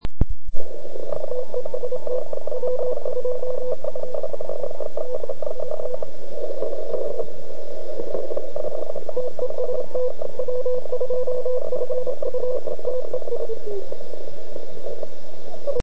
Jak poslouchala FT857?